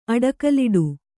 ♪ aḍakaliḍu